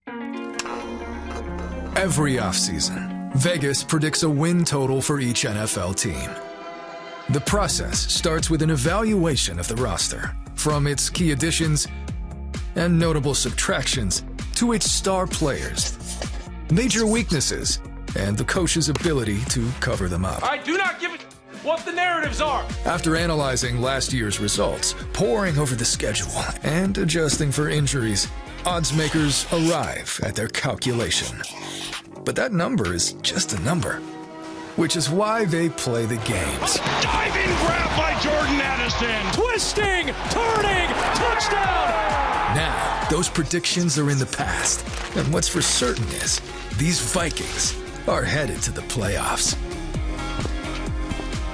Narration Download This Spot